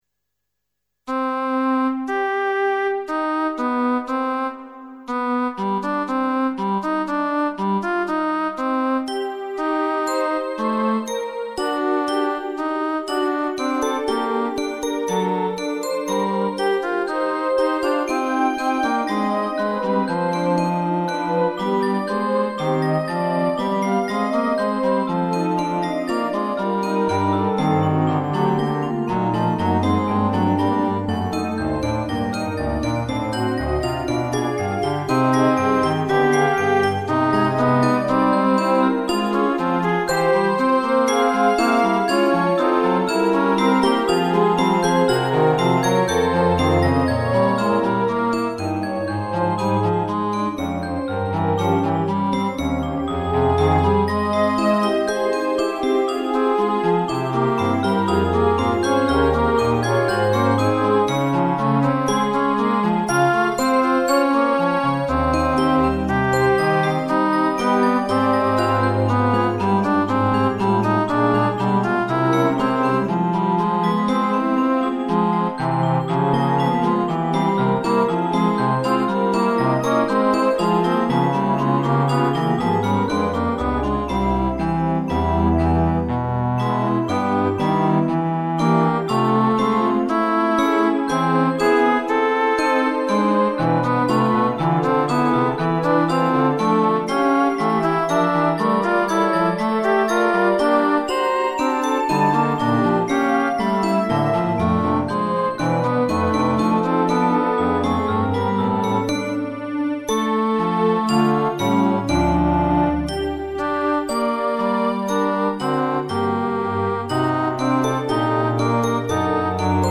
With a touch of Franck, but unfinished (stuck in sequential entanglements ;-)   mid pdf
Fuga 3 in c.mp3